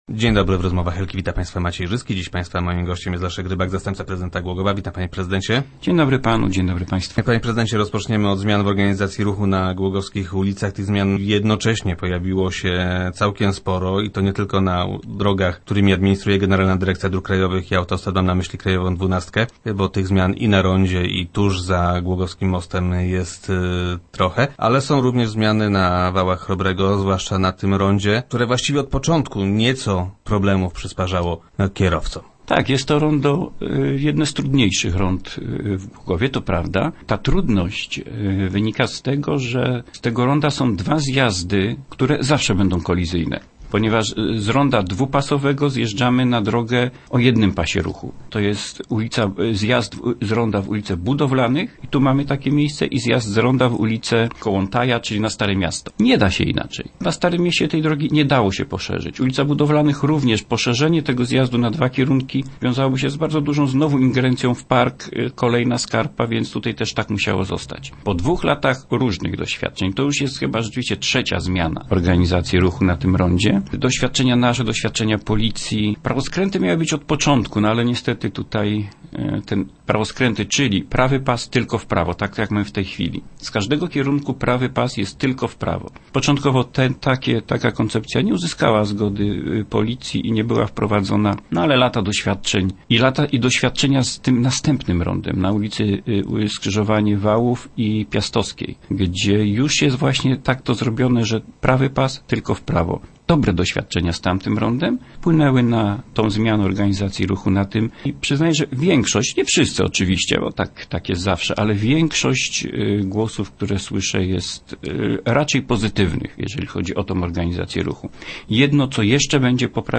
- Niestety jest kilkanaście usterek, ale nie są one  poważne - informuje Leszek Rybak, zastępca prezydenta Głogowa, który był gościem Rozmów Elki.